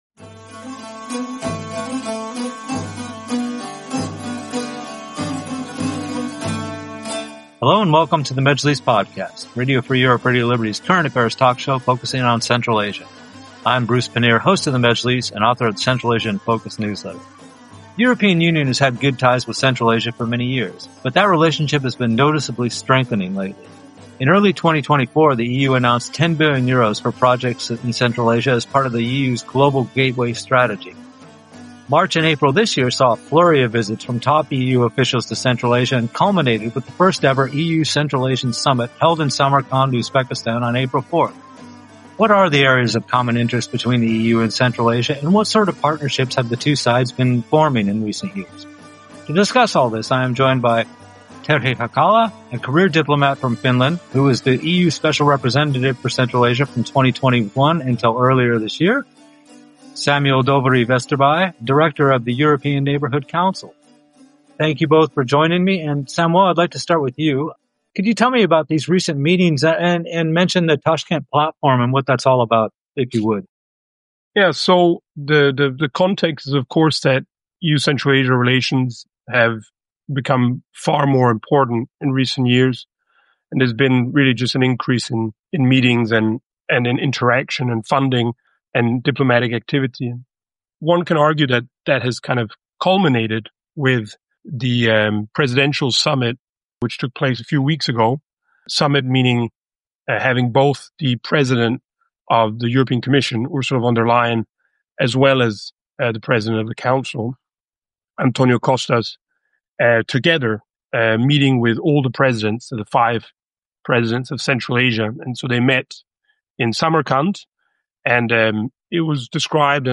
welcomes expert guests to discuss significant political developments and pressing social issues affecting the nations of Central Asia.